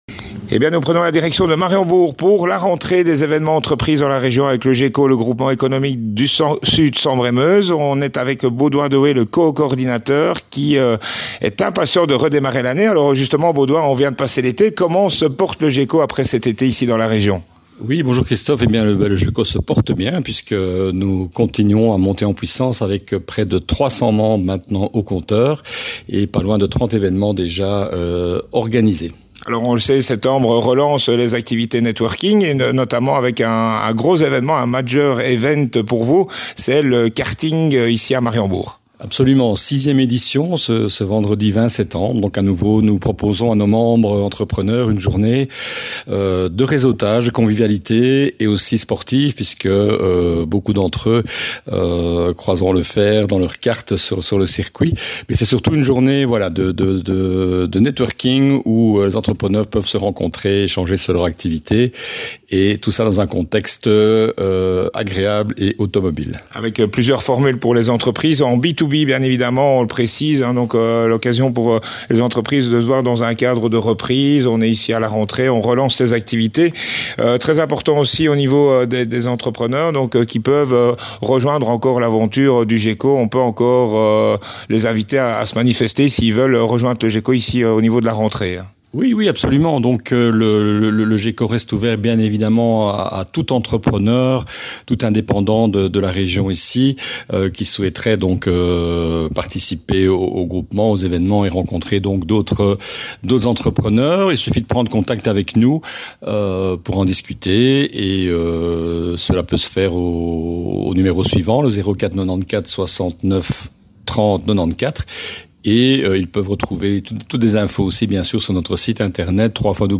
pour Flash fm vous parle de cet événement le 6ème GECO MOTOR DAY